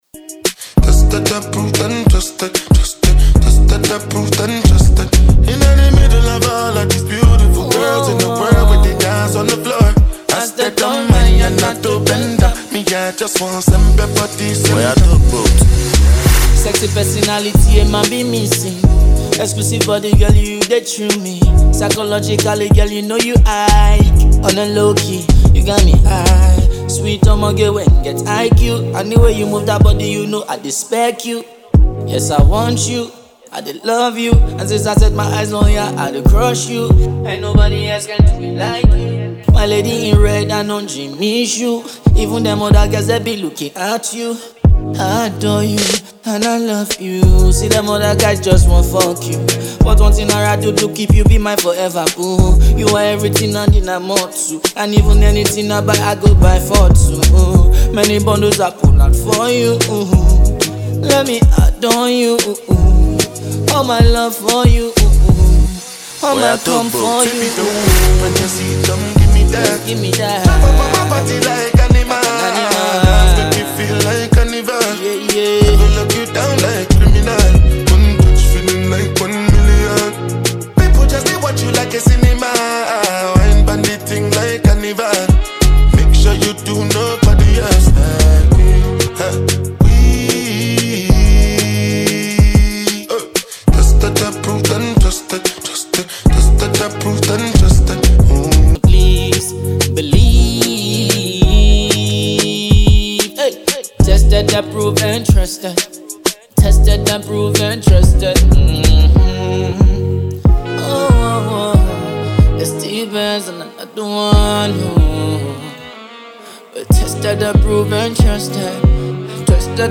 With a captivating melody and enchanting lyrics